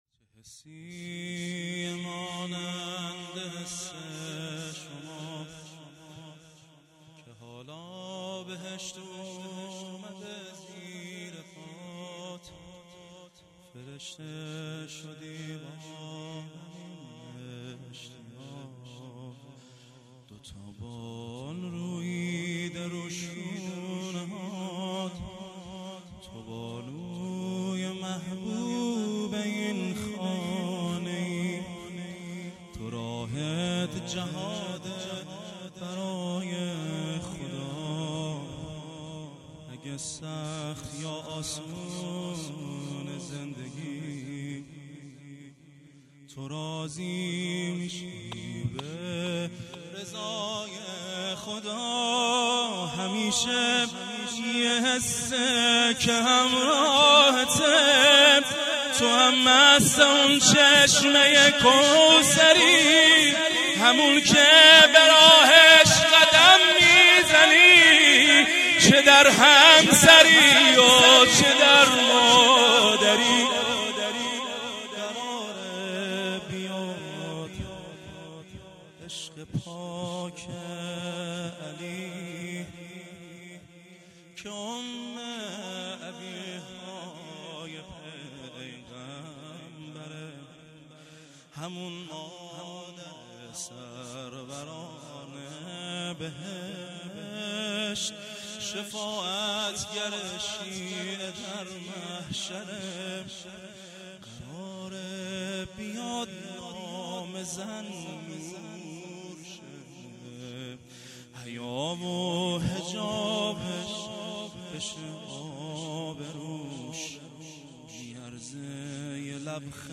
زمزمه - چه حسیه مانند حس شما
جشن ولادت حضرت زهرا(س)